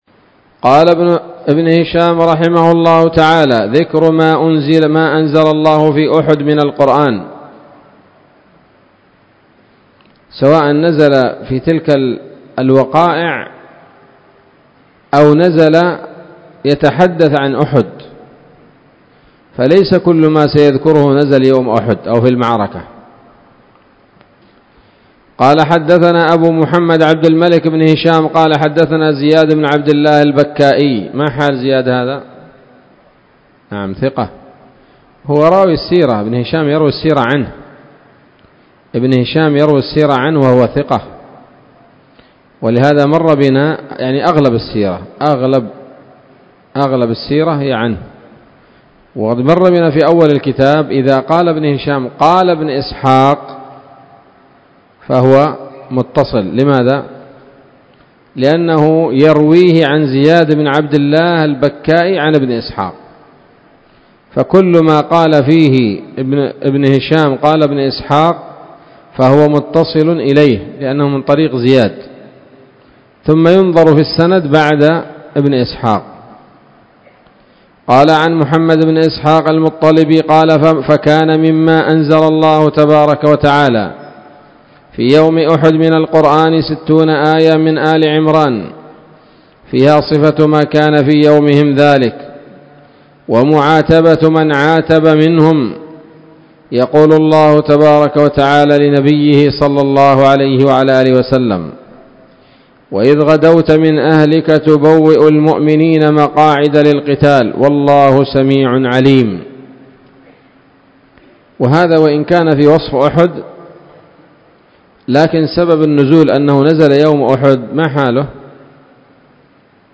الدرس التاسع والستون بعد المائة من التعليق على كتاب السيرة النبوية لابن هشام